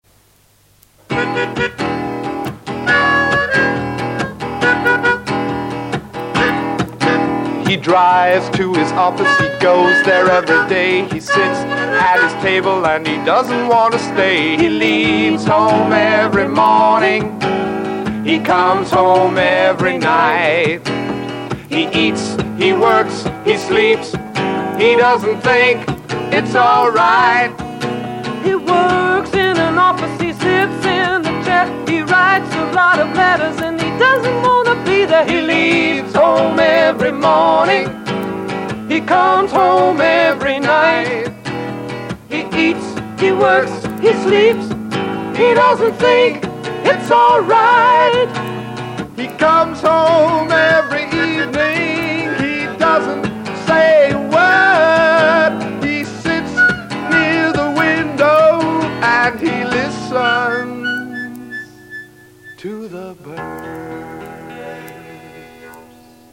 con esta canción no comercial